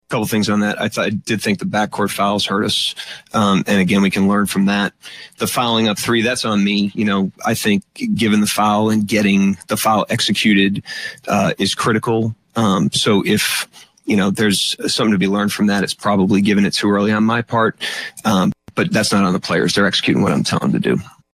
After the game head coach Mark Daigneault talked about the fouls.